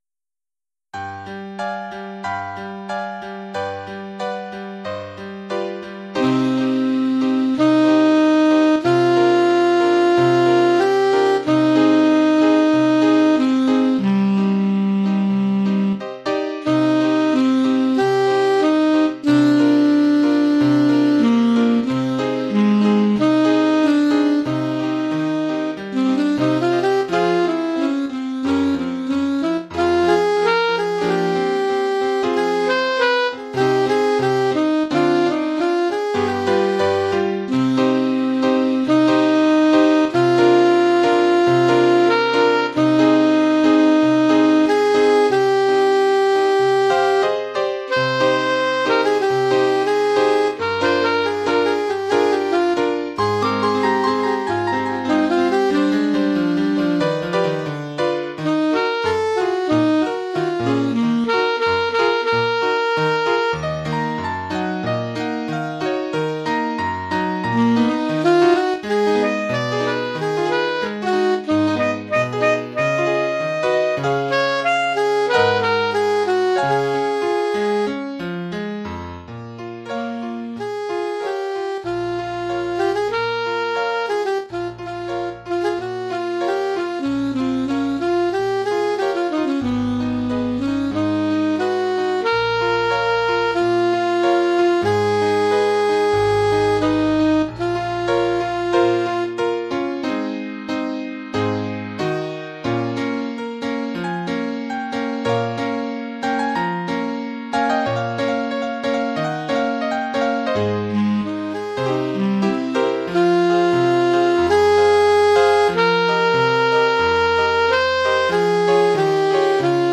Oeuvre pour saxophone alto et piano.
Cette pièce, très expressive, mérite bien son titre...